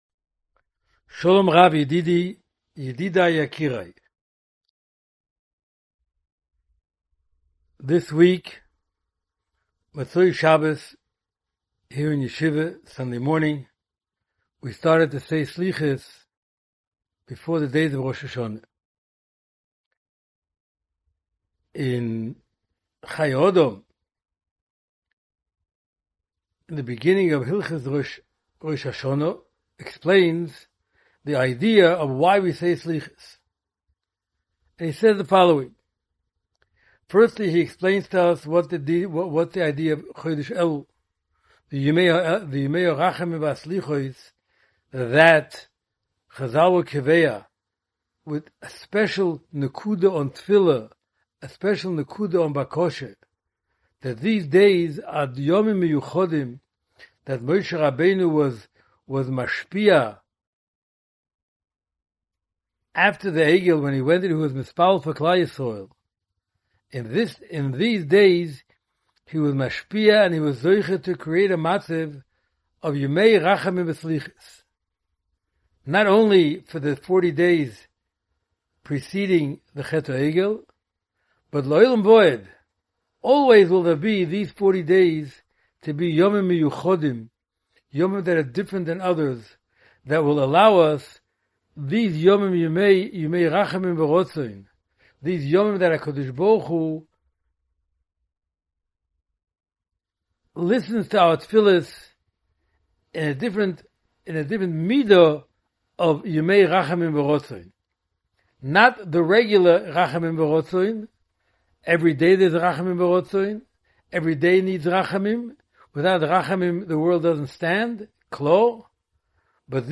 Parsha Preview Audio